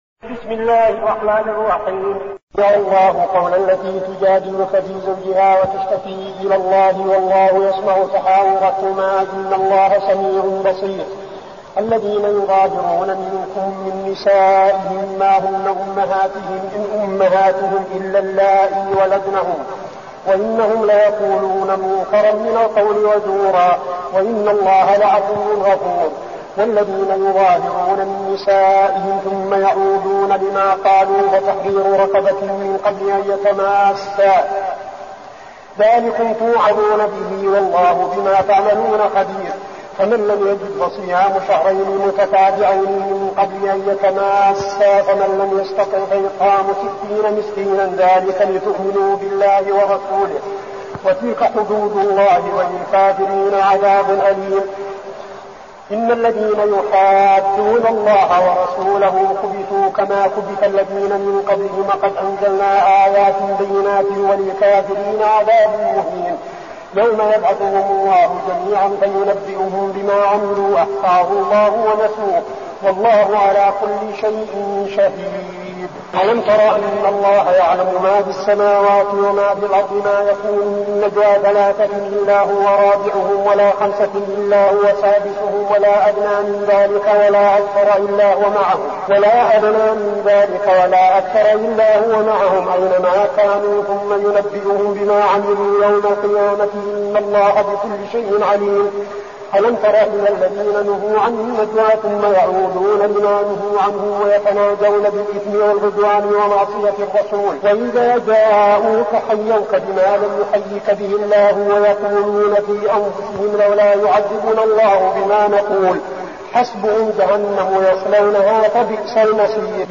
المكان: المسجد النبوي الشيخ: فضيلة الشيخ عبدالعزيز بن صالح فضيلة الشيخ عبدالعزيز بن صالح المجادلة